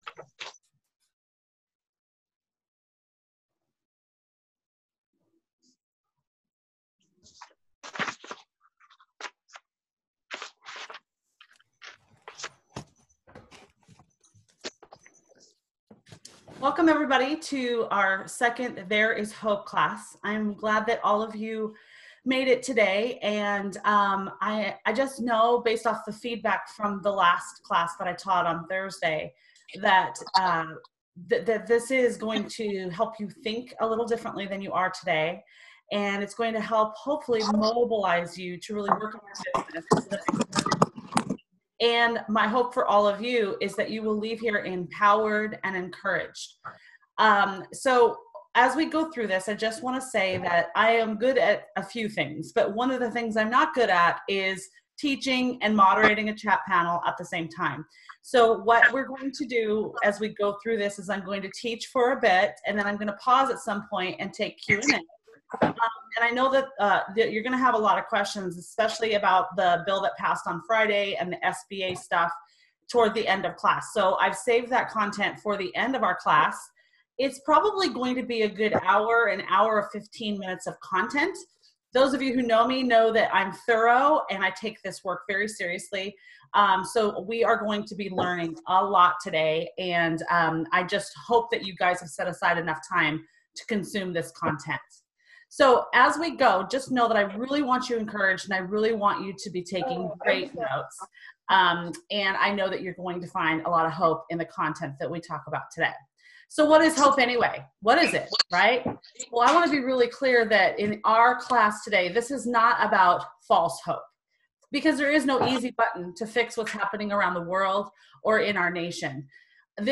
There is Hope (Full Webinar Audio).